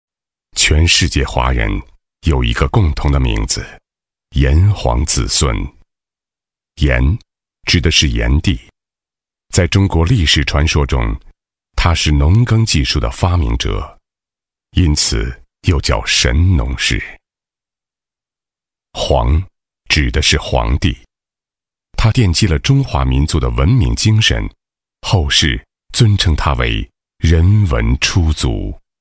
孙悦斌_纪录片_人文历史_炎黄子孙.mp3